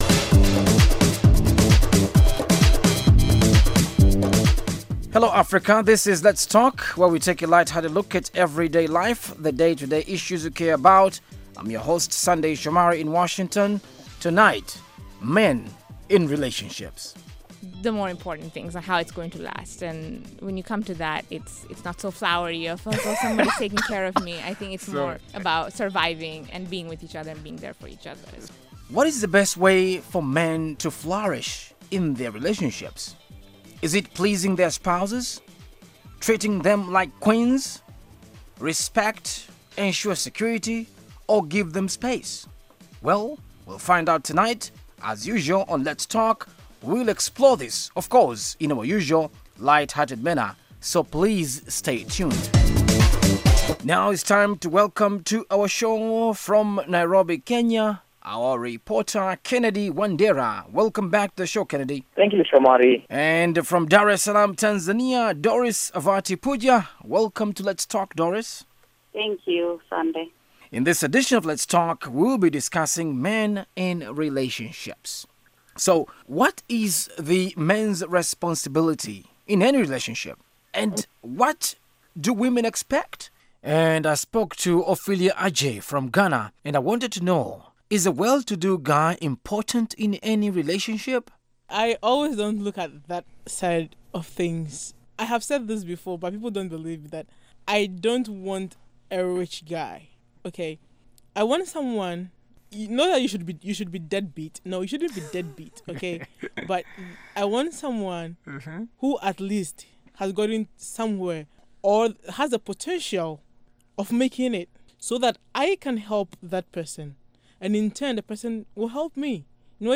Let's Talk is an interactive discussion program about lifestyle issues.